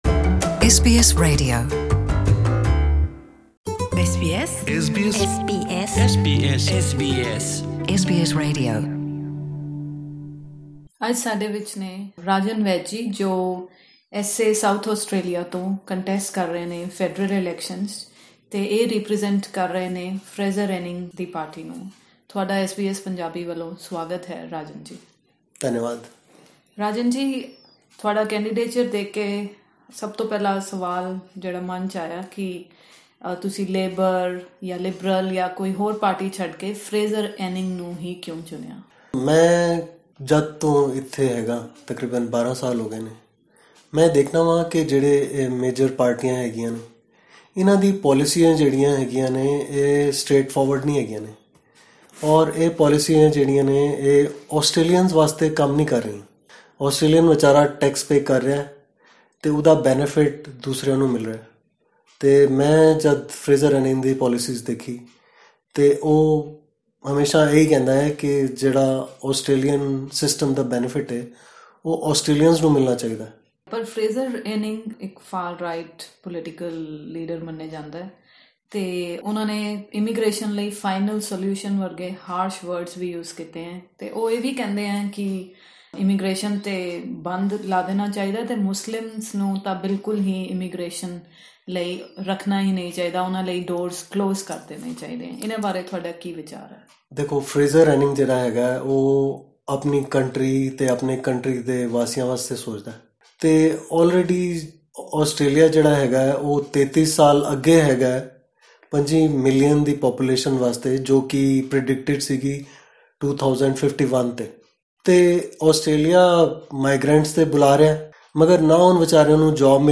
To listen to this interview in Punjabi, click on the player at the top of the page.